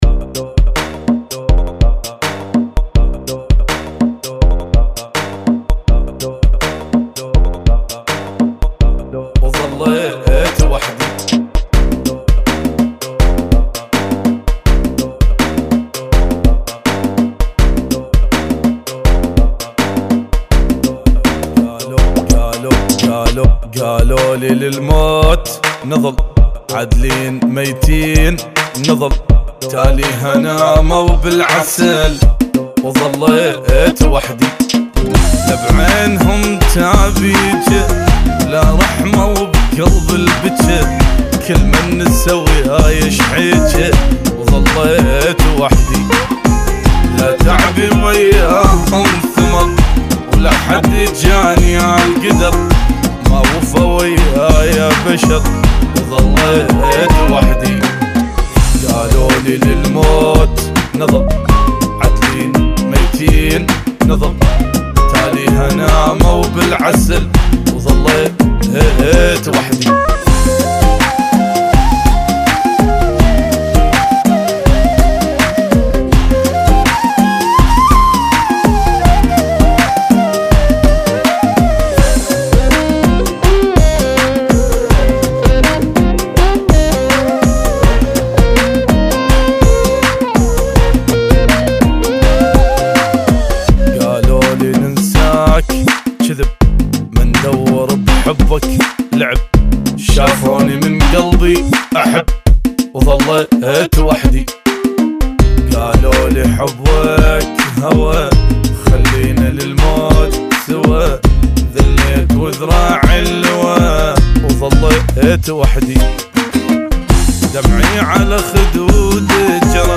Funky [ 82 Bpm